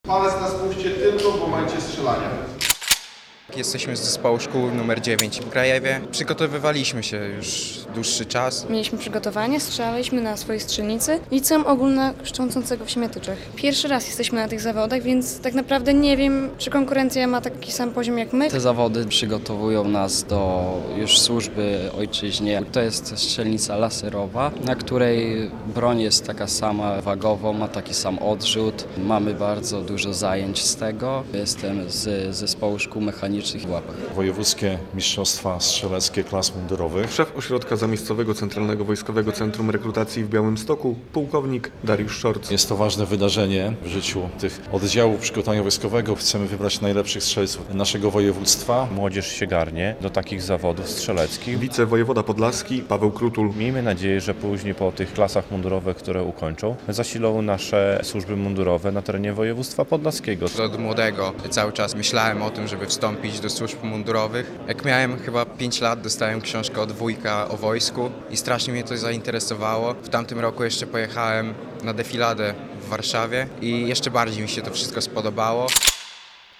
Drużyny ze szkół mundurowych walczą o mistrzostwo województwa podlaskiego w strzelectwie - relacja